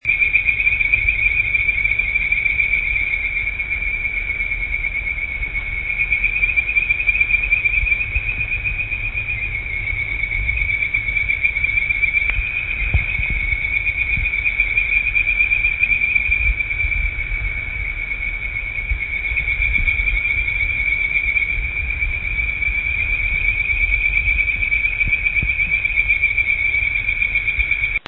Bird-voiced Treefrog’s Call
Once it reaches over 70* F, males will begin to call for mates during the day.
Call: The call sounds like a very birdlike with a series of rapid repeated, high-pitched, whistle like notes. Some consider that the cadence and syncopation sounds similar to pileated woodpecker.